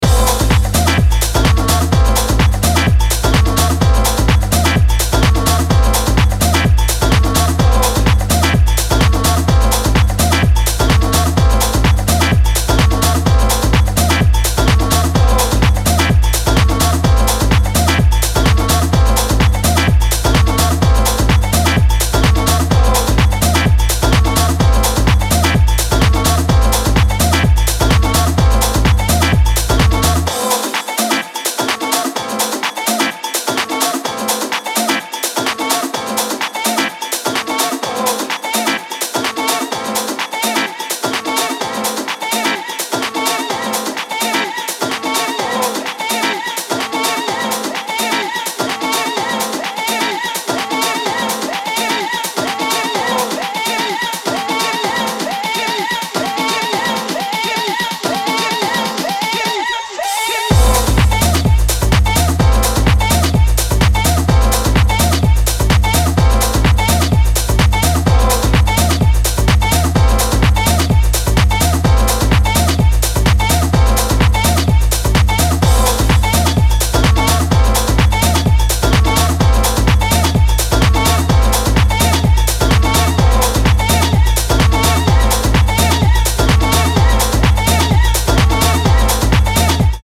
ピークタイムを駆け上り爆発するラテン・ハウス
クラシカルでオーセンティック、そしてソウルフルなハウスのフィーリングをモダンに昇華する